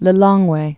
(lĭ-lôngwā)